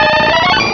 Cri d'Aéromite dans Pokémon Diamant et Perle.